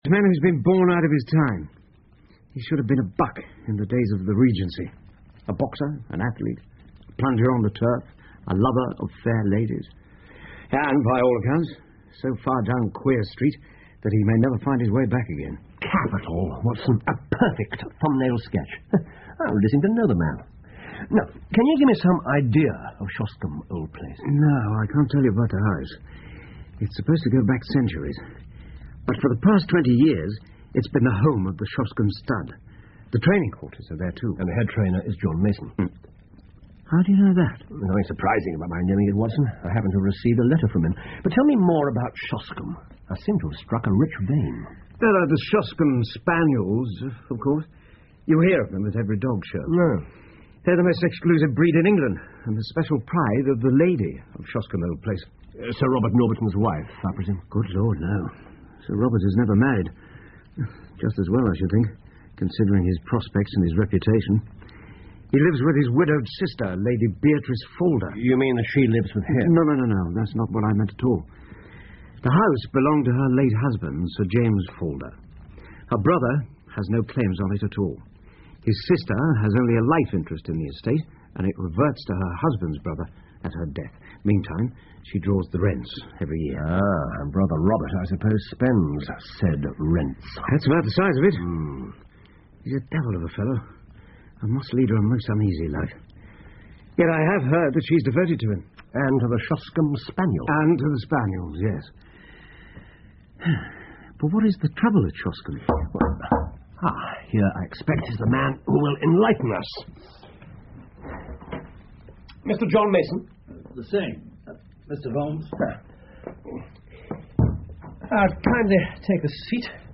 福尔摩斯广播剧 Shoscombe Old Place 2 听力文件下载—在线英语听力室